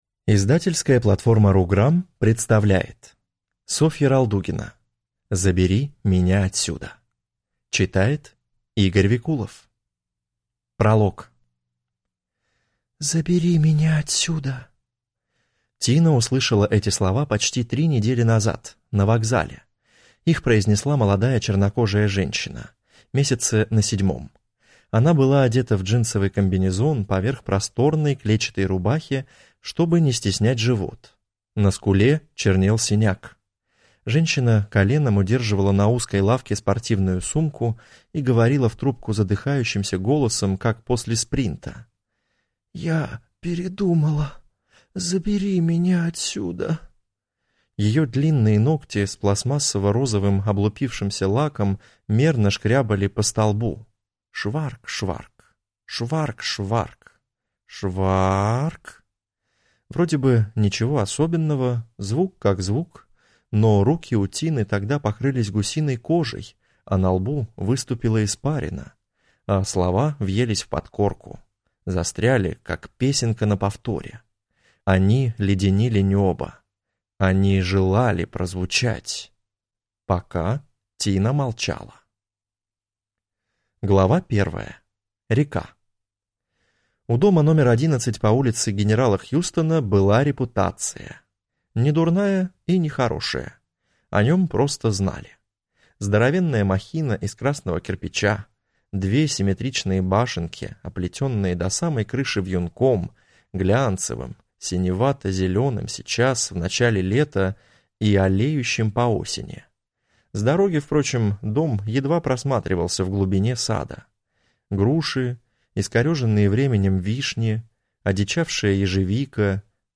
Аудиокнига Забери меня отсюда | Библиотека аудиокниг